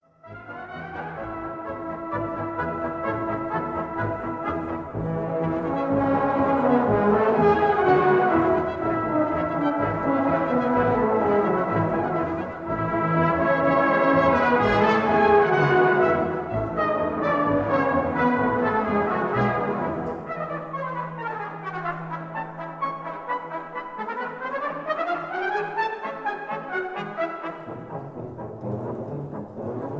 brass bands
1960 stereo recording